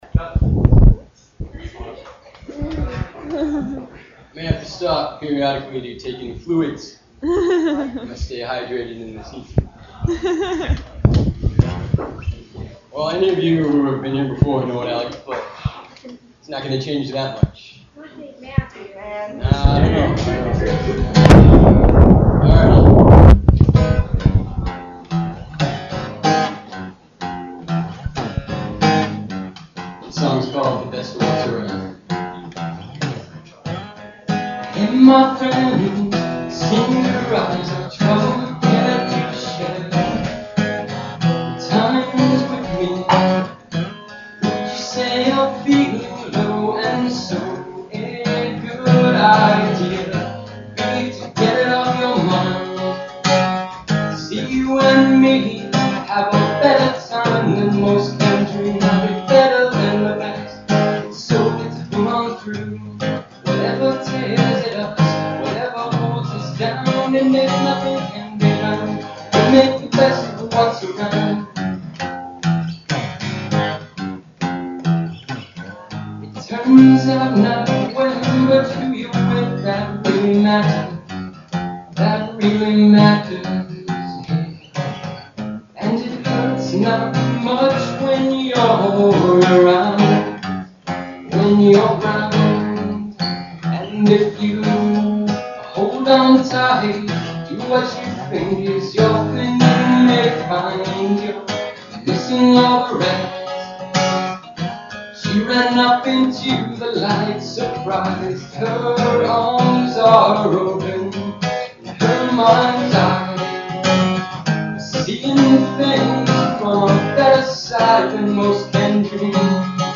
it was a mic stuck into a couch cushion, haha